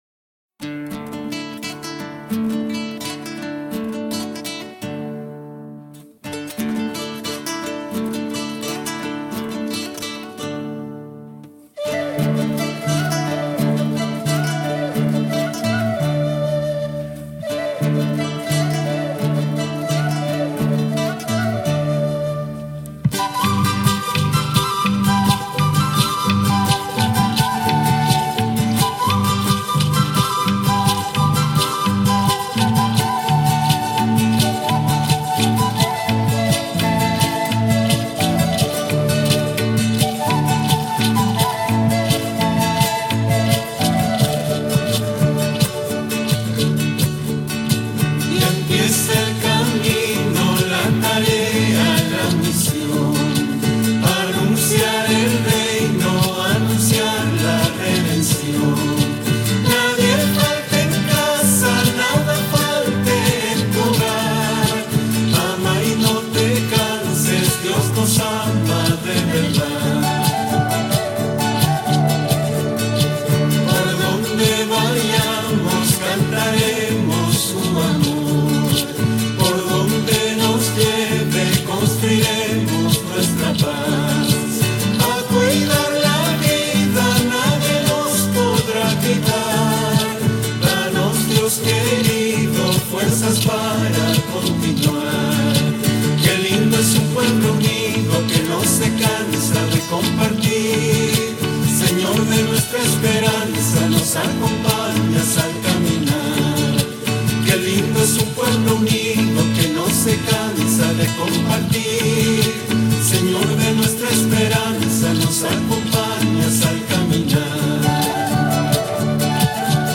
Antes de comenzar os dejamos una canción que ayude a entrar